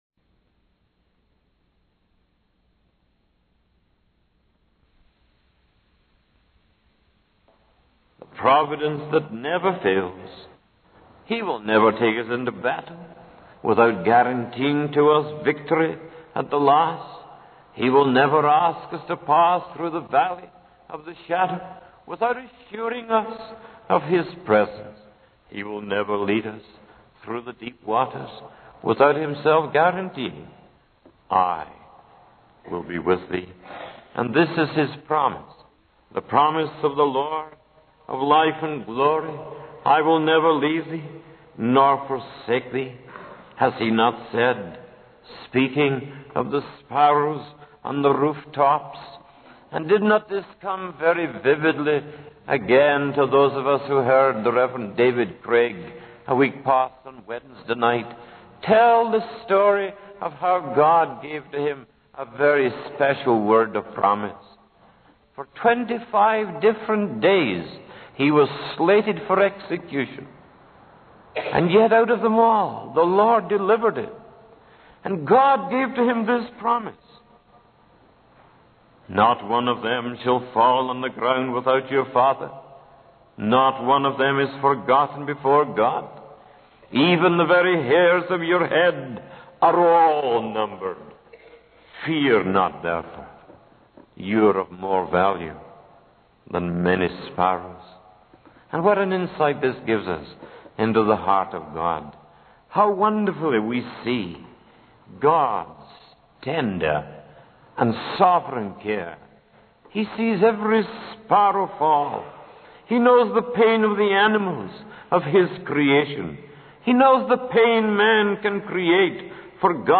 In this sermon, the preacher focuses on the theme of God's presence and care in the midst of pain and suffering. He emphasizes that God does not promise exemption from pain, but rather assures his children of his presence and support.